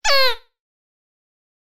CD-ROOM/Assets/Audio/SFX/grito5.wav at main
grito5.wav